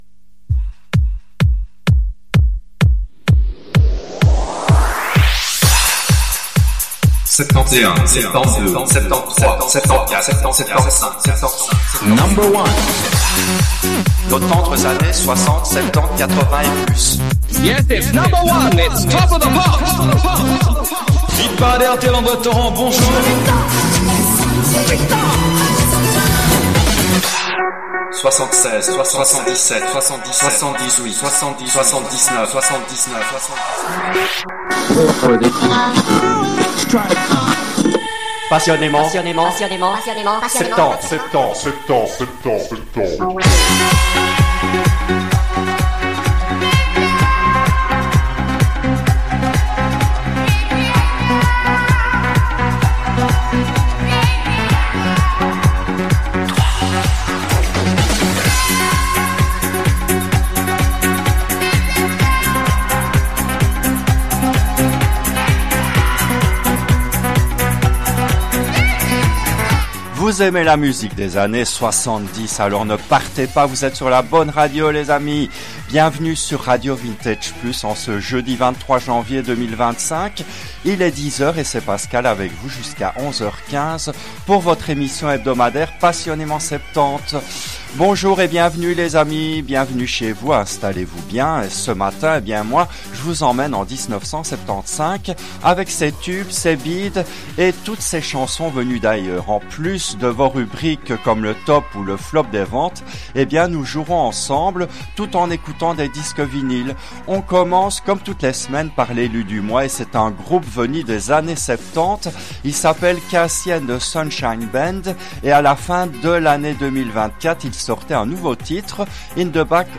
Cette émission a été diffusée en direct le jeudi 16 février 2023 à 10h, depuis les studios belges de RADIO VINTAGE PLUS.